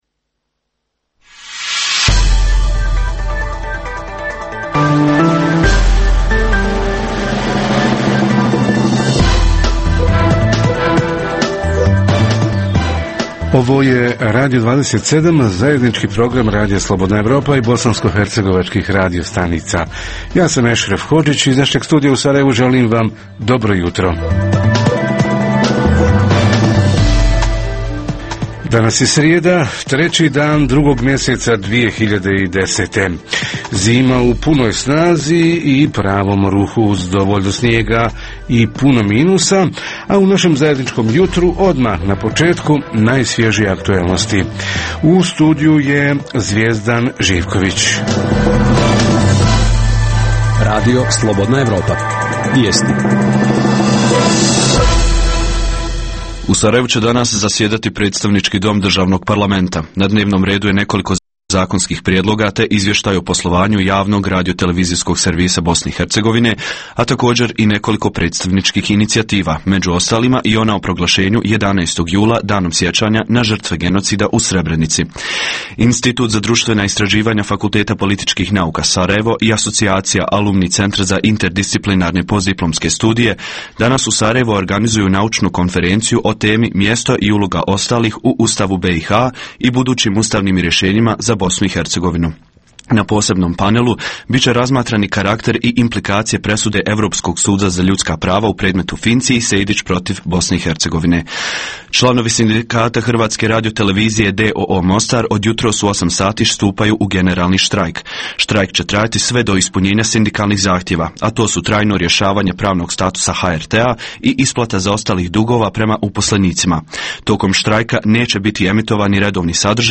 “Lokalno je primarno”, projekat misije OSCE-a u BiH – jačanje lokalne zajednice kroz komunikaciju na relaciji općinska administacija – mediji – građani – kako da građani budu bolje informisani o lokalnim događajima? Reporteri iz cijele BiH javljaju o najaktuelnijim događajima u njihovim sredinama.